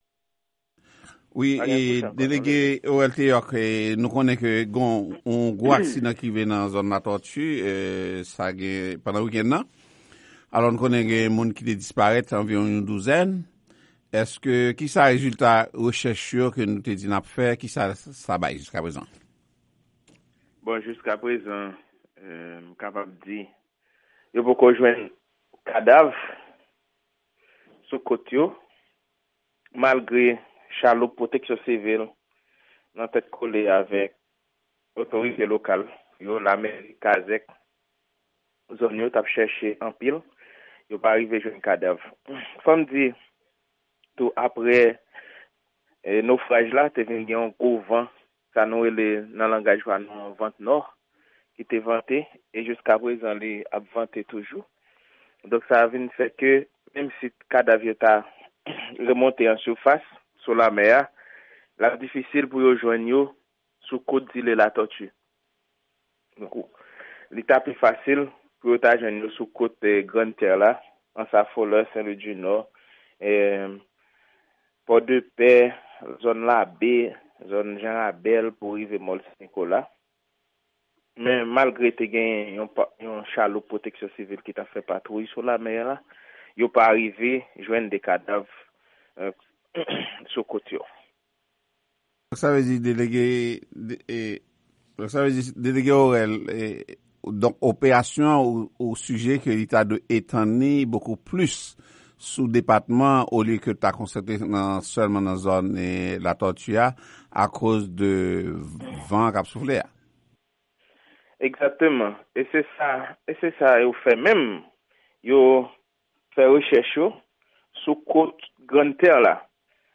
Entèvyou ak Delege Nòdwès la sou Aksidan Bato a